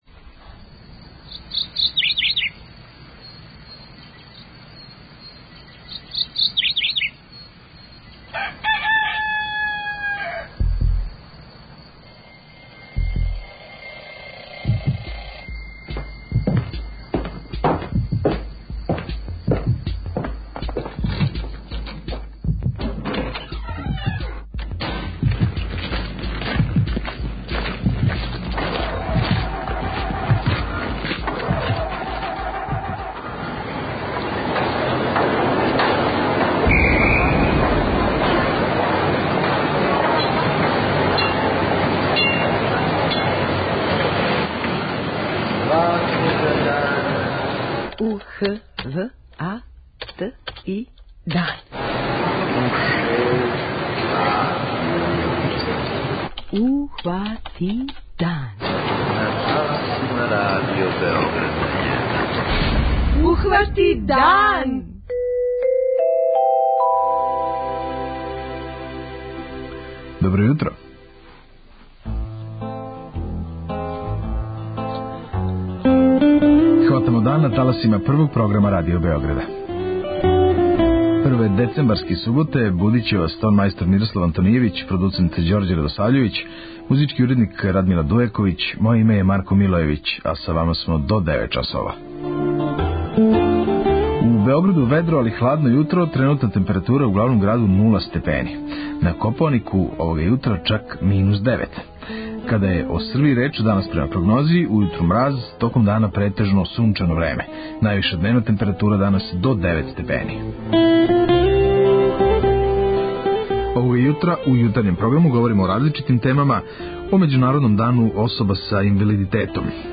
Трећи сат Јутарњег програма суботом је посвећен туризму.